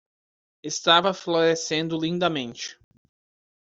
Pronounced as (IPA) /ˌlĩ.daˈmẽ.t͡ʃi/